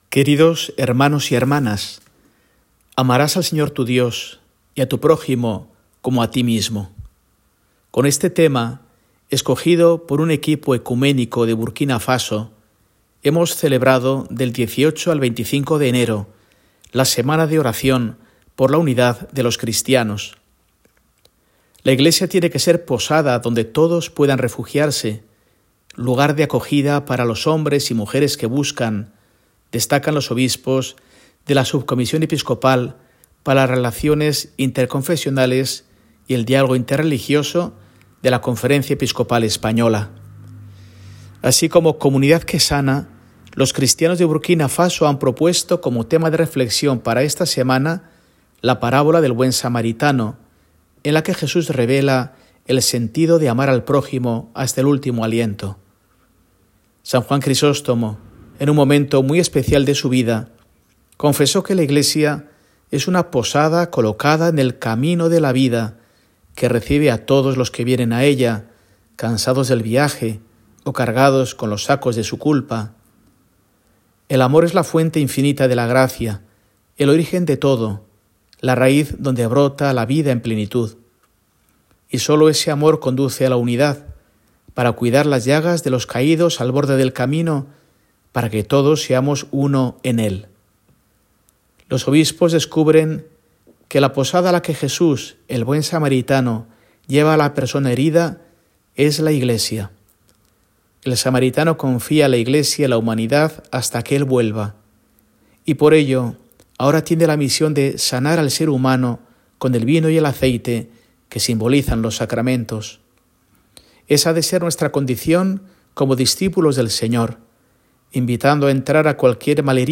Mensaje de Mons. Mario Iceta Gavicagogeascoa, arzobispo de Burgos, para el domingo, 28 de enero de 2024
Escucha el mensaje de Mons. Iceta para este domingo